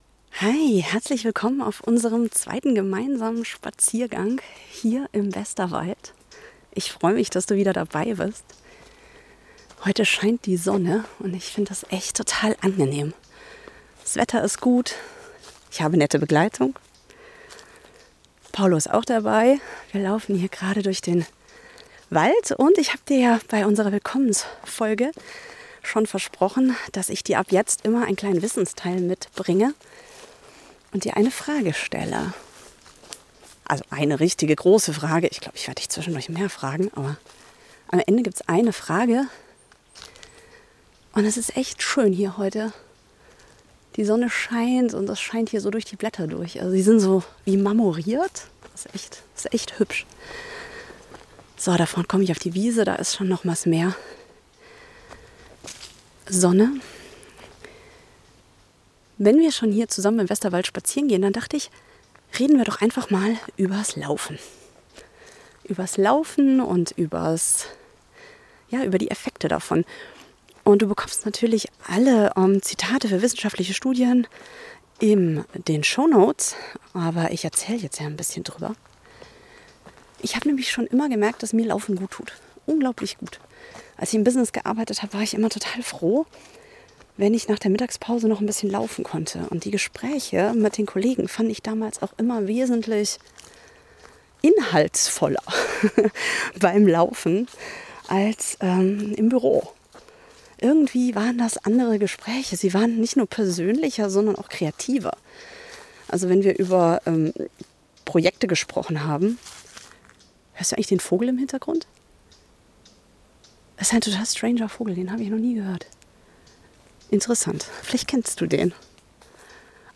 Wir spazieren zusammen durch den sonnigen Westerwald und ich